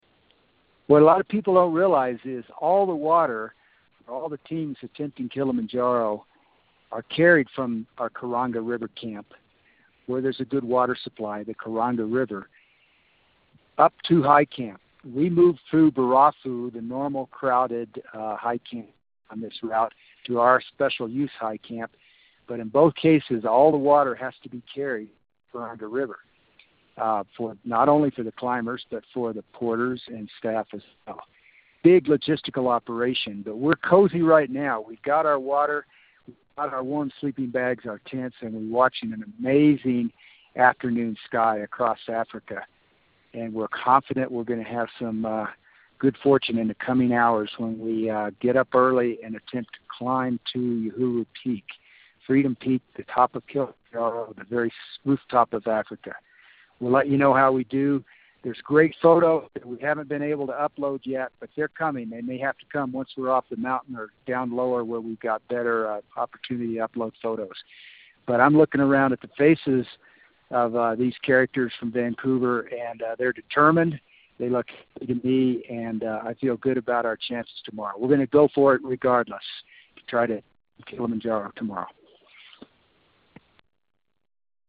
August 4, 2016 – Call from the Most Amazing Place, Kilimanjaro High Camp at 16,000ft above Sea Level